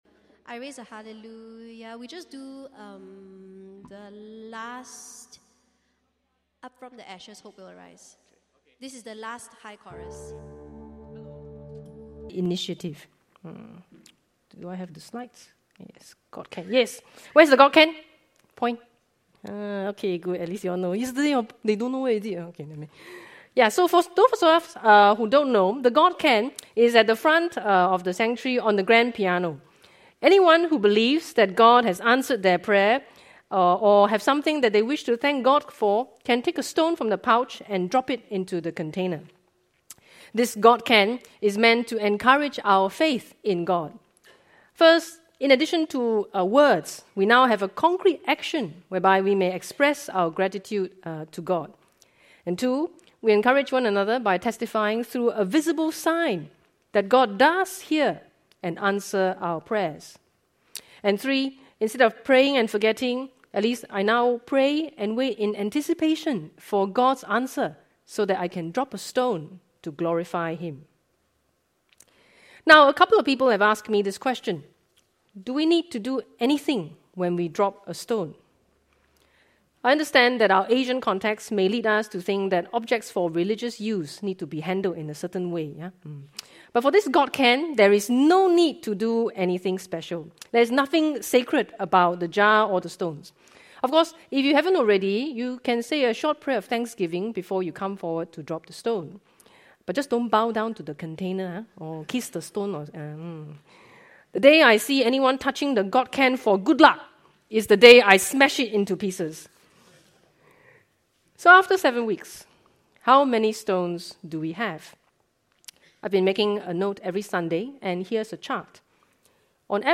10 Dec 2023 Advent MP Service